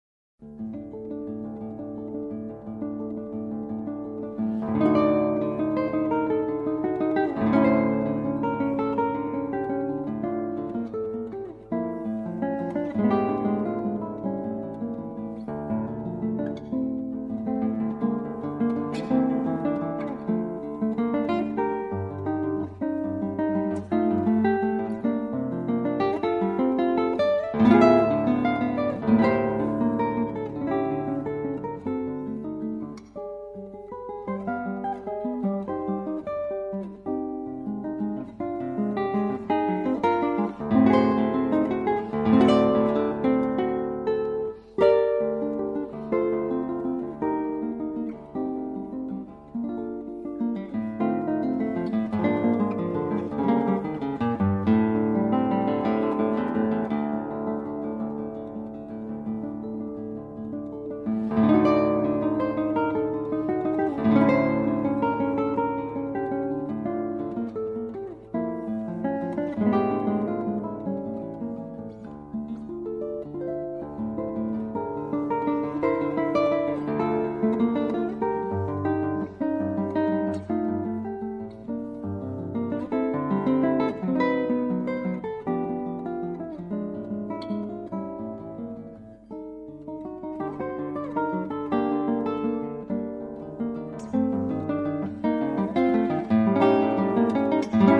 Classical Guitar